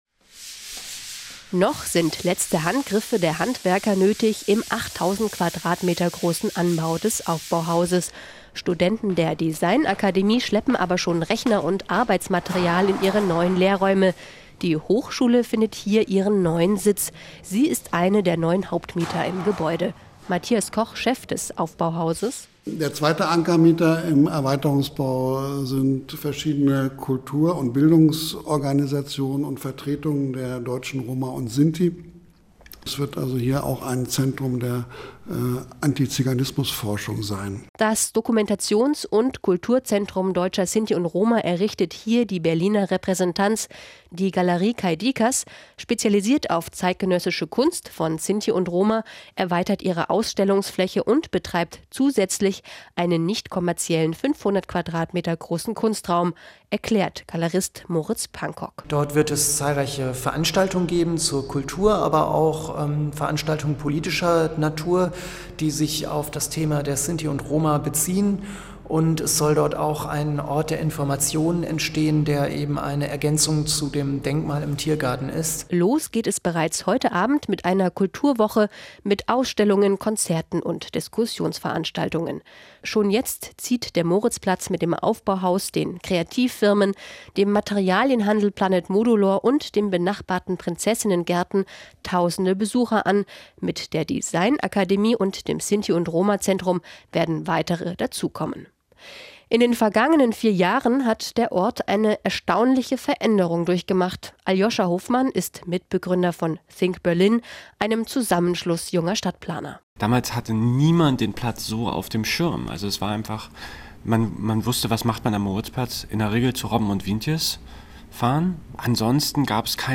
Gesendet am : 07.04.2015 um 9:55 Uhr im RBB Inforradio: AUFBAU ohne Ende am Moritzplatz